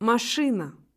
Trois consonnes sont toujours dures: Ц, Ж, Ш